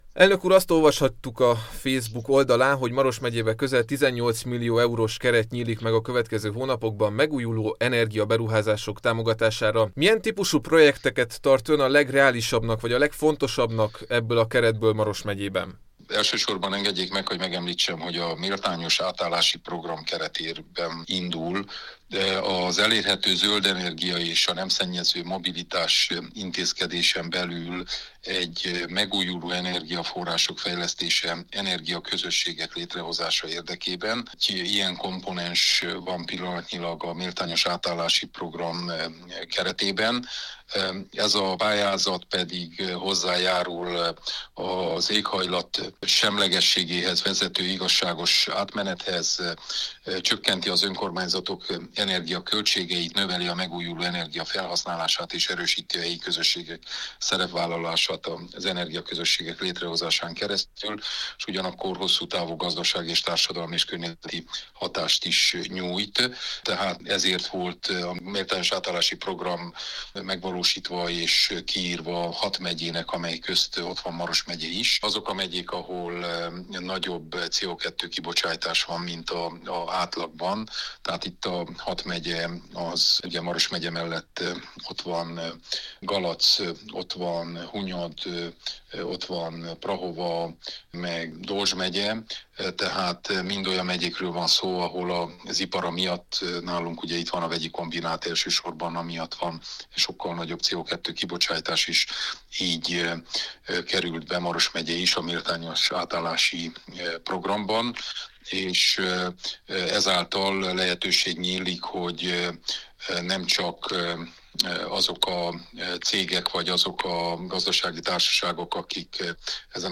Maros megyében közel 18 millió eurós keret nyílik meg a következő hónapokban megújuló energia-beruházások támogatására – erről számolt be Péter Ferenc, Maros Megye Tanácsának elnöke.